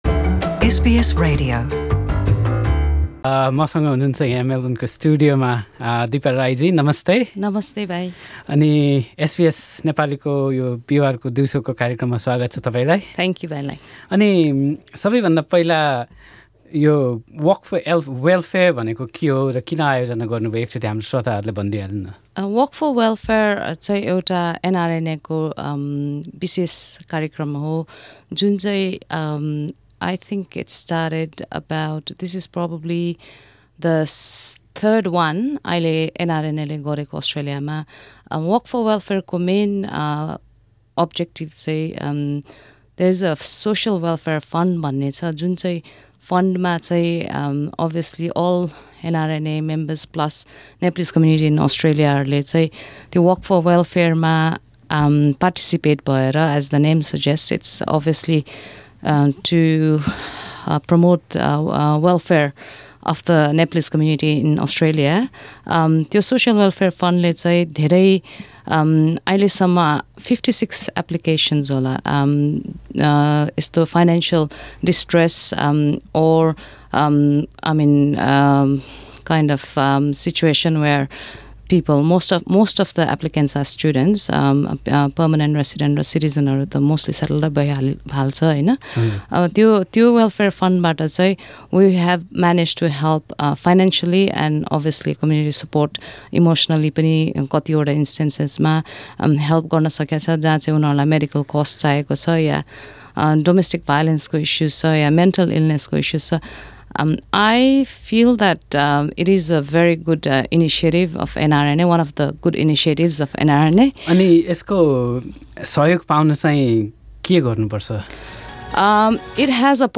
She spoke to SBS Nepali.